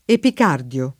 vai all'elenco alfabetico delle voci ingrandisci il carattere 100% rimpicciolisci il carattere stampa invia tramite posta elettronica codividi su Facebook epicardio [ epik # rd L o ] s. m.; pl. ‑di (raro, alla lat., -dii )